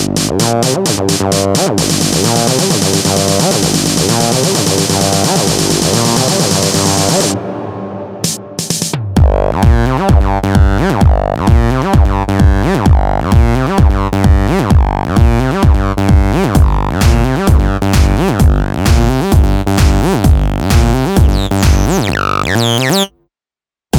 no Backing Vocals R'n'B / Hip Hop 3:17 Buy £1.50